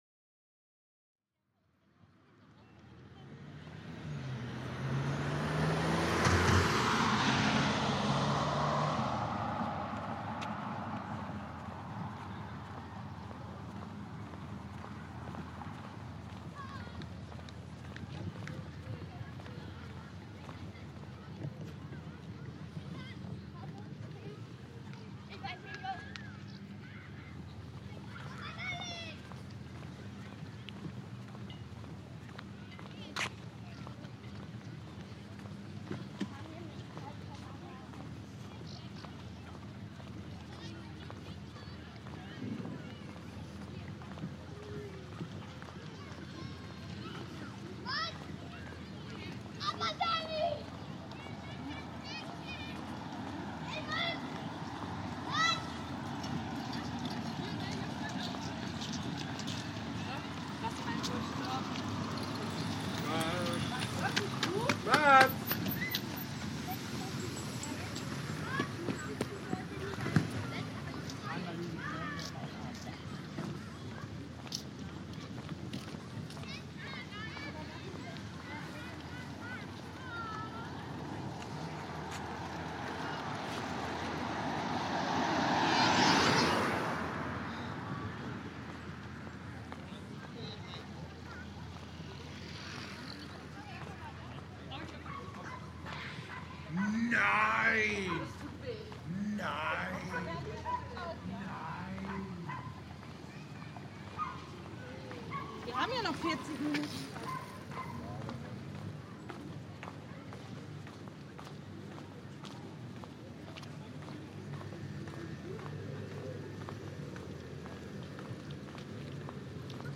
Bei schönem Wetter ist die Strandpromenade voller Menschen, jeder geht seinem Ferien-Tagwerk nach.
Even though it gets a bit quieter towards the south beach, you're still a long way from true solitude by the sea...
Use headphones to benefit from the binaural effect.